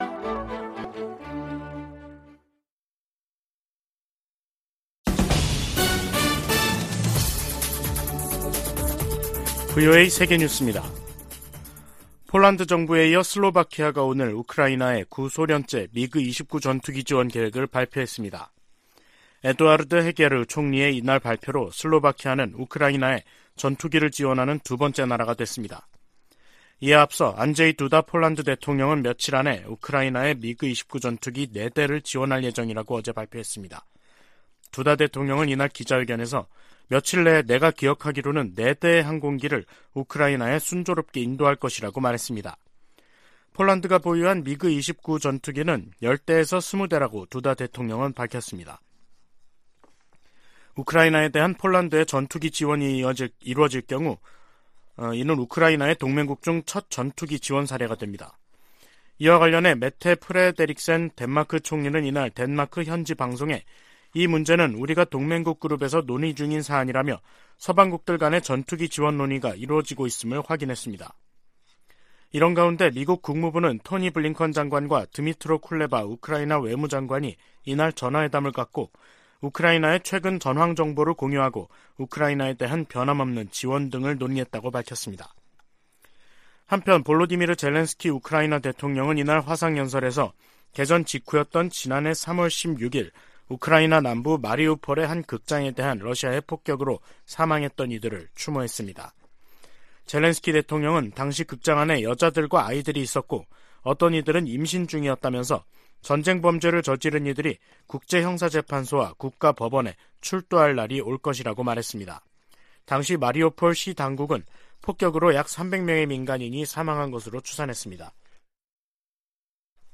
VOA 한국어 간판 뉴스 프로그램 '뉴스 투데이', 2023년 3월 17일 2부 방송입니다. 백악관이 16일 열린 한일 정상회담에 적극적인 환영과 지지 입장을 밝혔습니다. 북한은 16일 대륙간탄도미사일 (ICBM) '화성-17형' 발사 훈련을 실시했다고 밝히고, 그 신뢰성이 검증됐다고 주장했습니다. 미 국방부는 북한의 지속적 도발에도 불구하고 대북 억제력이 작동하고 있다고 강조했습니다.